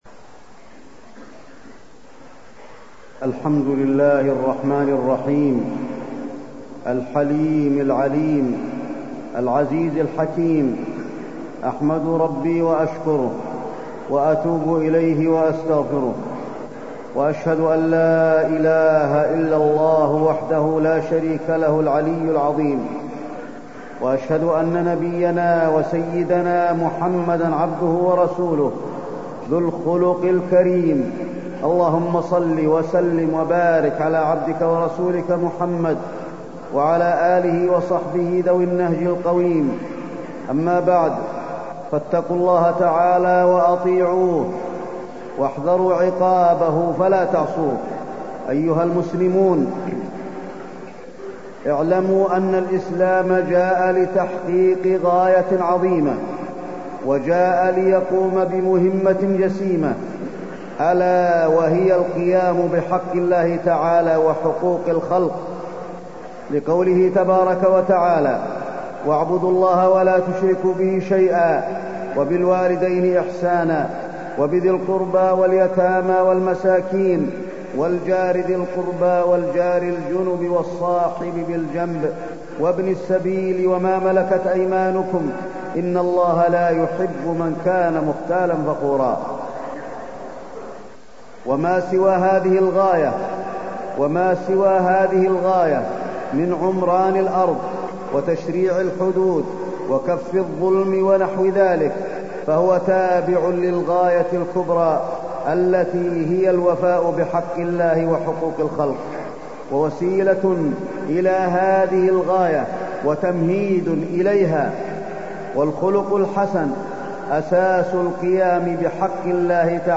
تاريخ النشر ٢٧ ذو الحجة ١٤٢٣ هـ المكان: المسجد النبوي الشيخ: فضيلة الشيخ د. علي بن عبدالرحمن الحذيفي فضيلة الشيخ د. علي بن عبدالرحمن الحذيفي الخلق الحسن